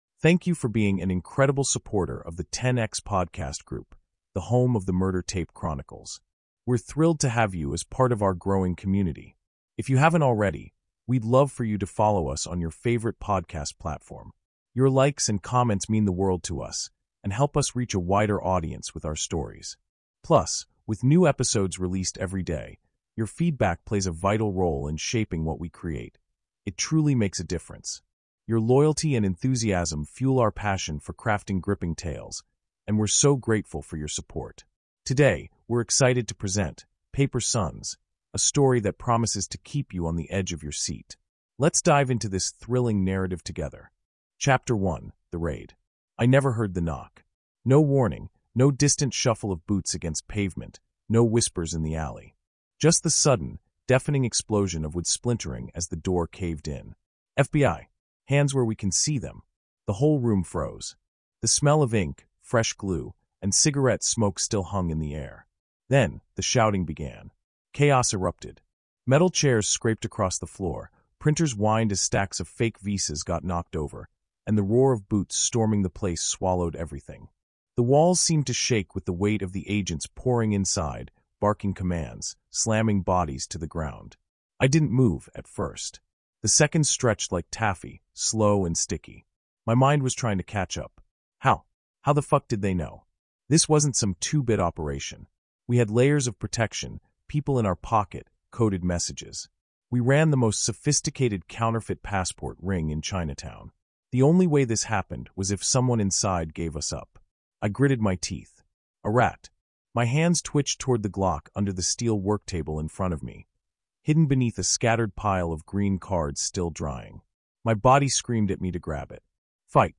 Paper Sons | Audiobook